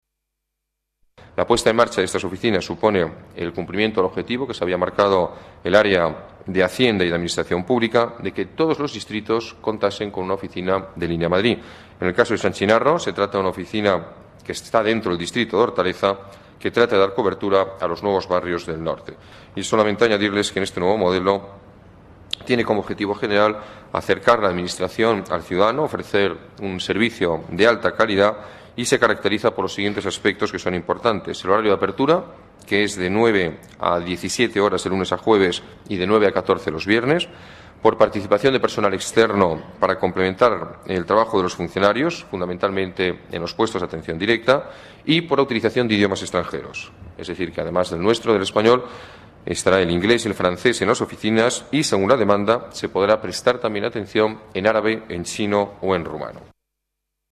Nueva ventana:Declaraciones del alcalde sobre las nuevas oficinas de atención al ciudadano